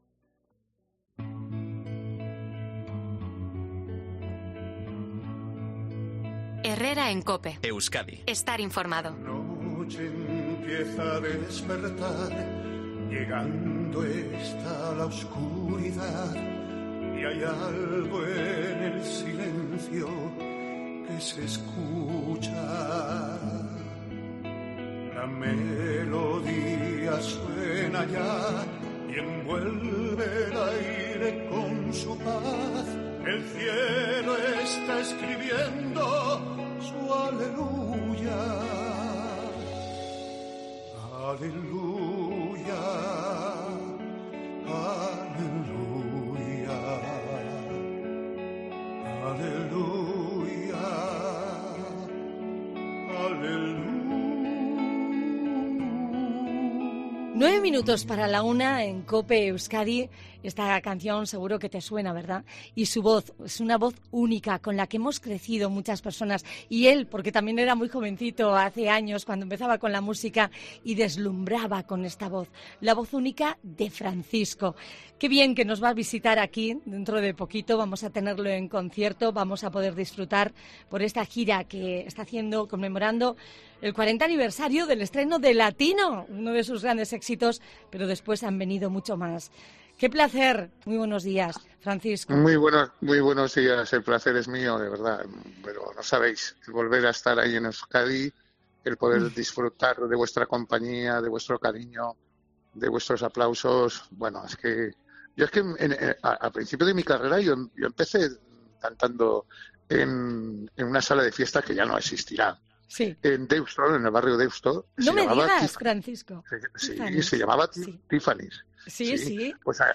Entrevista a Francisco